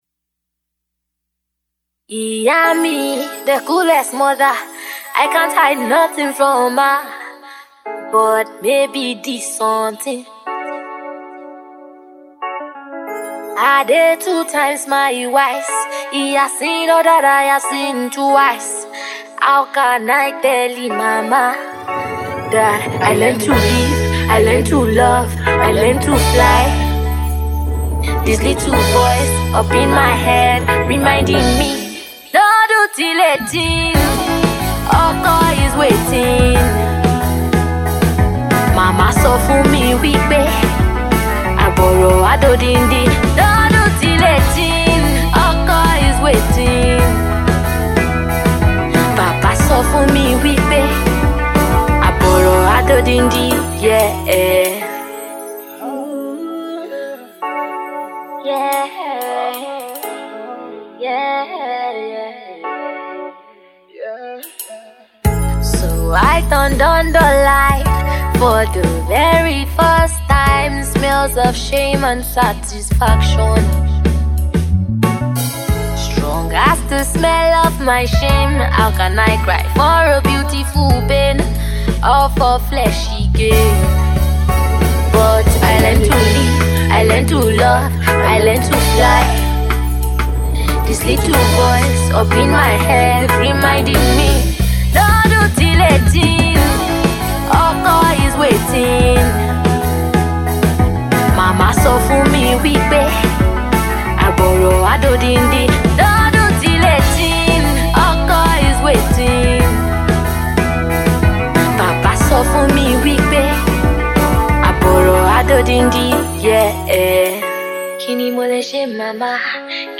Alternative Soul